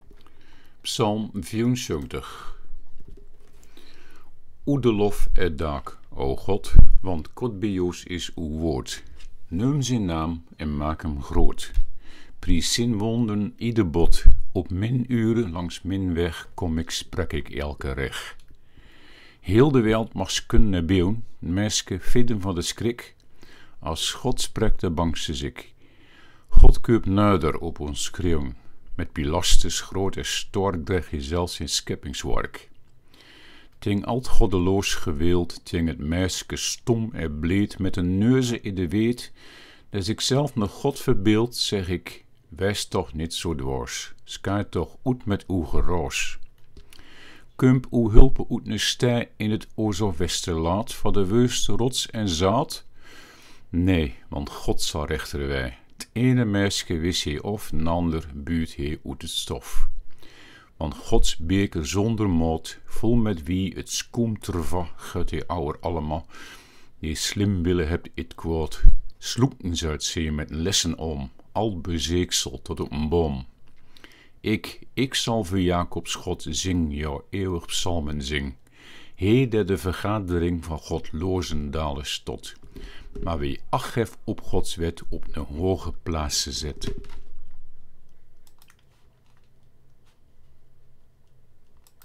Luisterversie